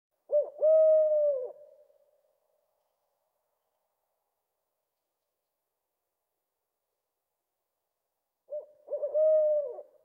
Sound Effects
Owl In The Forest Close Up